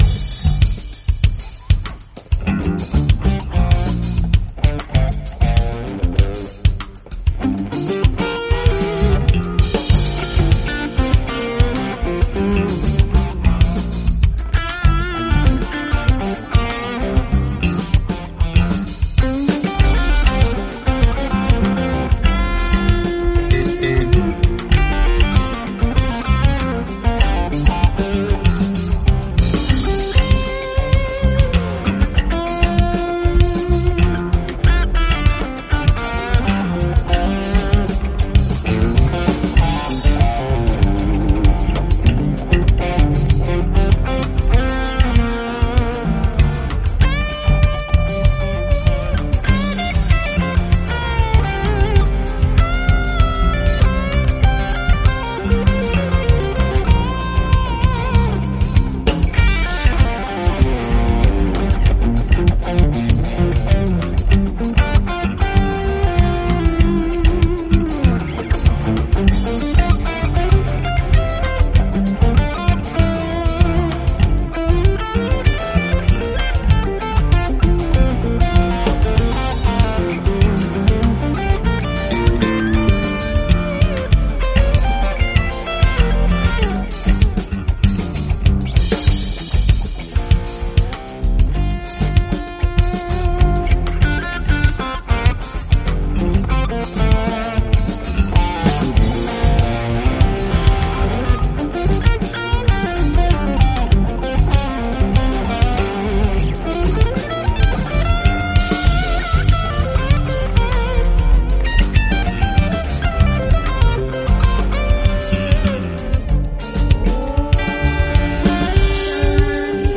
Guitars, bass, sound fx and drum programming.
Recorded, mixed and mastered in Presonus Studio One 4.6 at home Studio in San Luis Obispo, Ca.